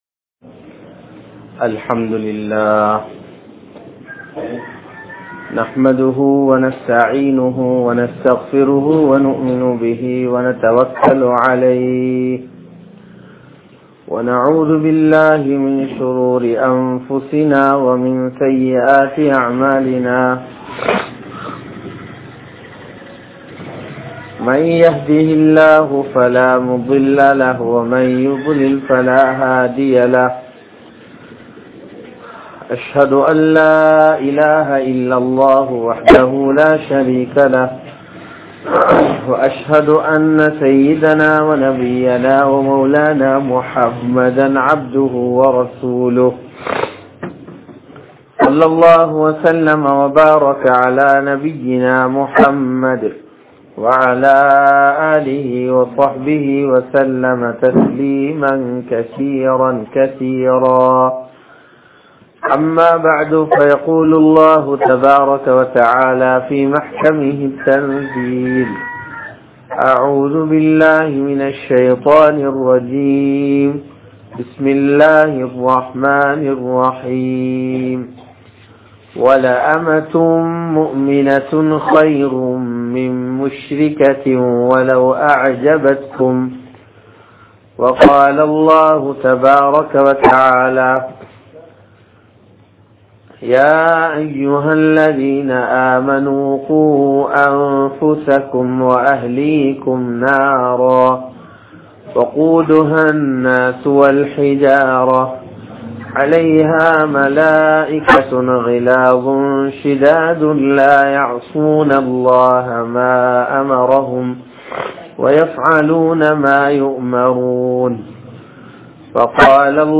Suvarkaththai Adaium Vali (சுவர்க்கத்தை அடையும் வழி) | Audio Bayans | All Ceylon Muslim Youth Community | Addalaichenai